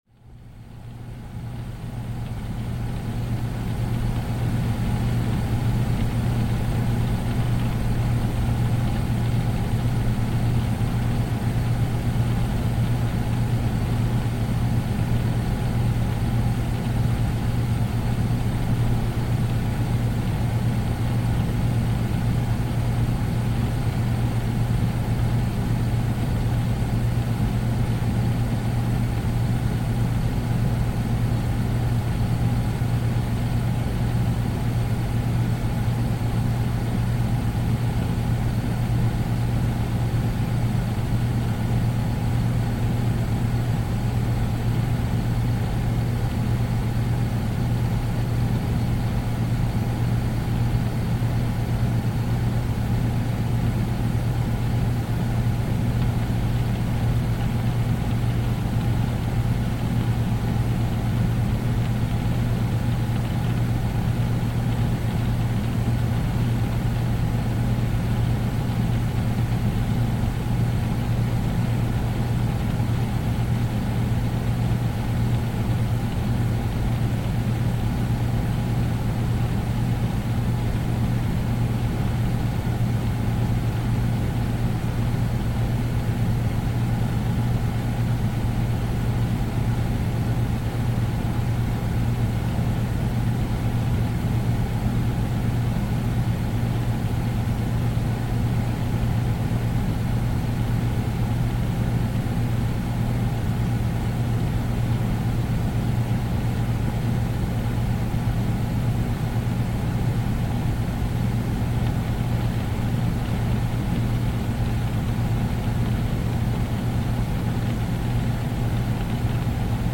Rain and Thunder Marathon: Meditation and Deep Focus
(Ads may play before the episode begins.)The rain begins softly, a gentle rhythm across the roof, then deepens into a powerful harmony of thunder and calm.